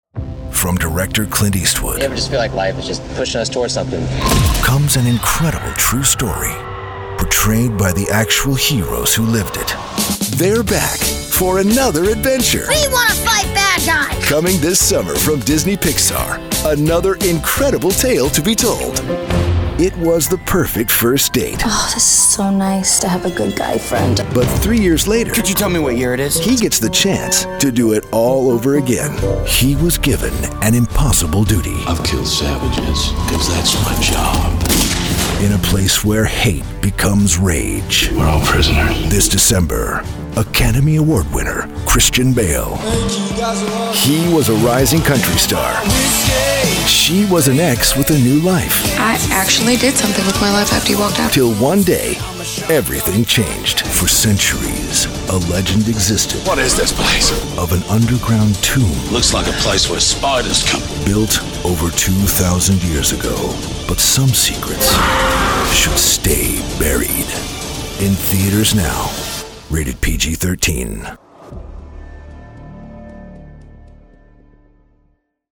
Movie Trailer
His rugged baritone is well-traveled, trustworthy, believable and altogether gripping. It can be intimate and inviting, or intense and commanding.